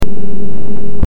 Electronic Pulse 04
electronic_pulse_04.mp3